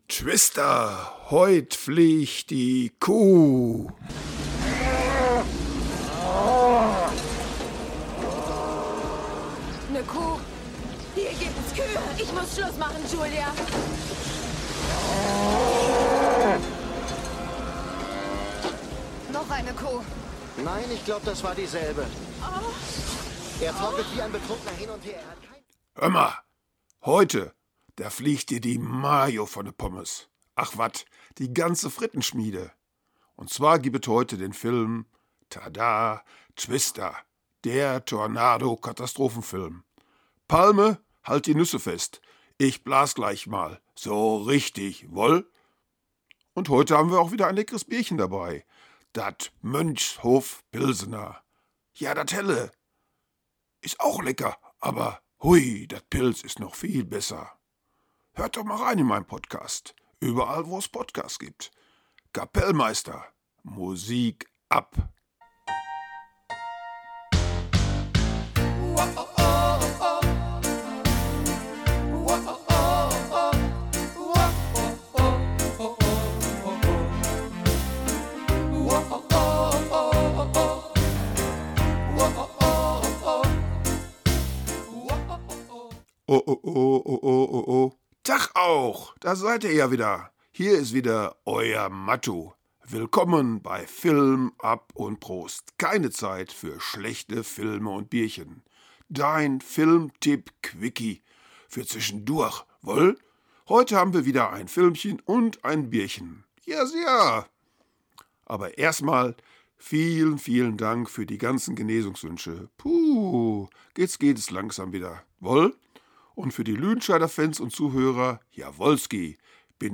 Der Filmquickie - Tipp mit Ruhrpottcharme und lecker Bierchen